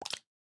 minecraft / sounds / mob / frog / tongue4.ogg
tongue4.ogg